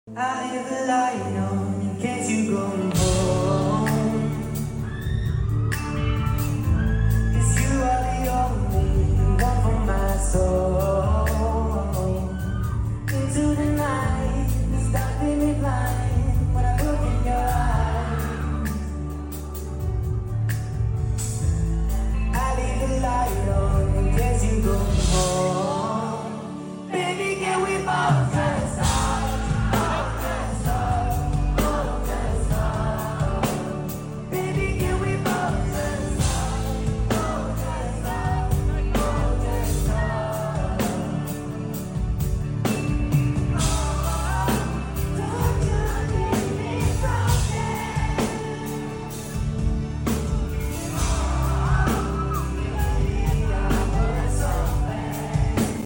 His singing voice is so cozy❤‍🩹